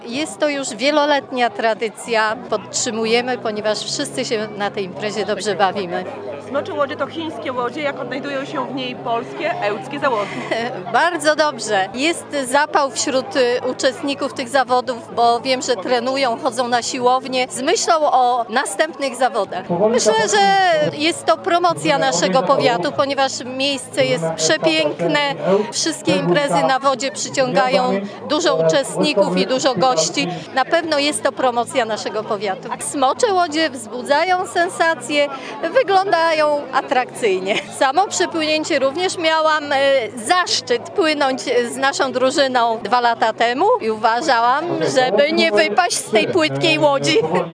– Smocze łodzie w Ełku to już tradycja – zaznacza Anna Iwaszko, wicestarosta powiatu ełckiego.